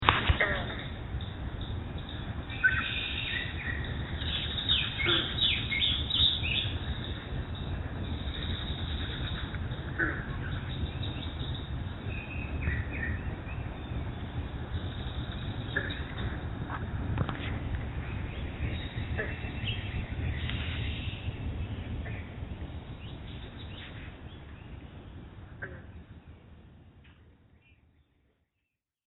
Here is a recording of some green frogs (Lithobates clamitans) calling to one another in the beaver pond (the banjo-like thwang under the sound of the very loud birds):